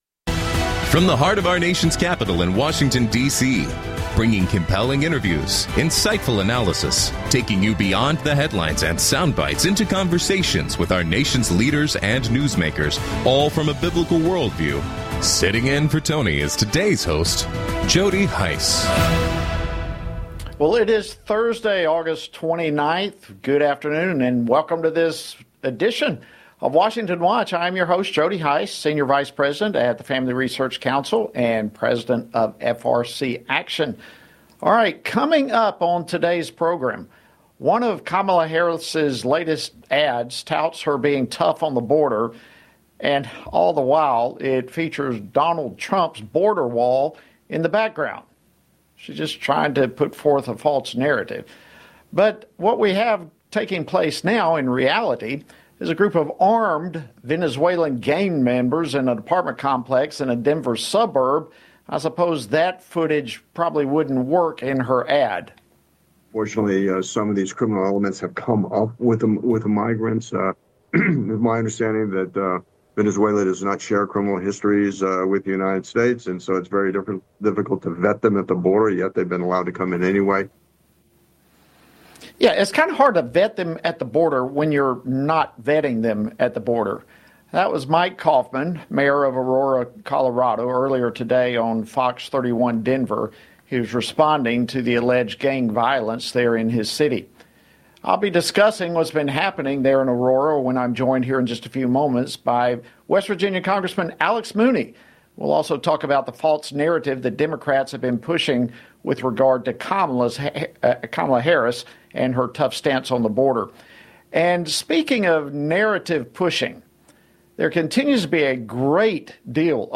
On today’s program, hosted by Jody Hice: Alex Mooney, U.S. Representative for the 2nd District of West Virginia, refutes Vice President Kamala Harris’s claim of being tough on border security and reacts to reports of an armed Venezuelan gang violently taking over an Aurora, Colorado apartment complex.